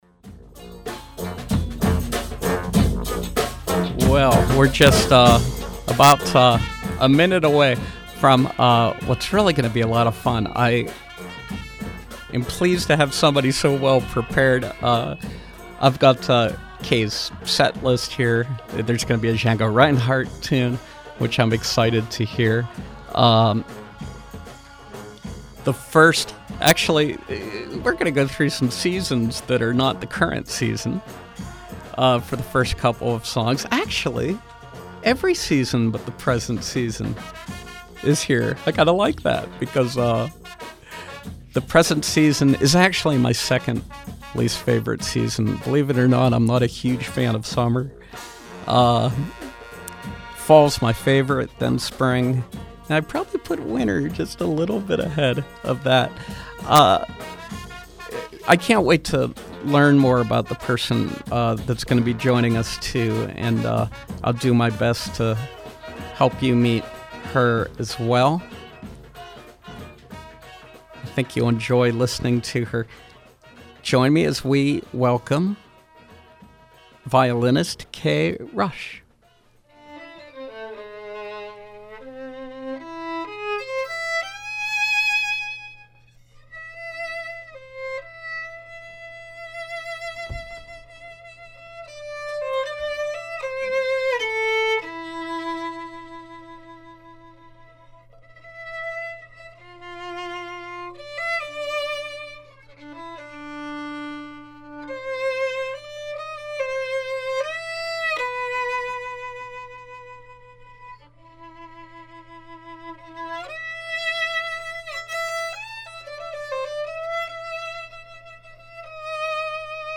Jazz violinist